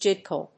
読み方ジェイ・ディー・ケー